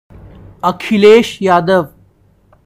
pronunciation; born 1 July 1973)[2] is an Indian politician and national president of the Samajwadi Party who served as the 20th Chief Minister of Uttar Pradesh.[3] Having assumed the chief minister's office on 15 March 2012 at the age of 38, he is the youngest person to have held the office till date.[4] He is the incumbent Member of Parliament for Kannauj in the 18th Lok Sabha.
Akhilesh_Yadav_pronunciation.ogg.mp3